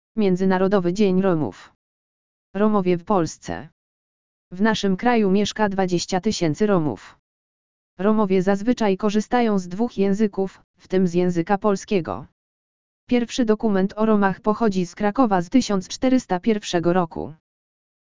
AUDIO LEKTOR MIĘDZYNARODOWY DZIEŃ ROMÓW
audio_lektor_miedzynarodowy_dzien_romow.mp3